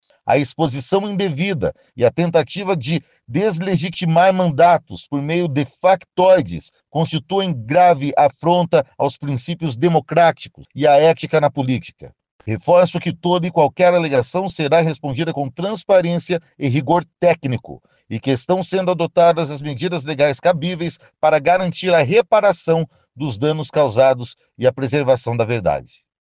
O vereador falou que essas denúncias têm sido feitas em âmbito nacional.